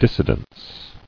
[dis·si·dence]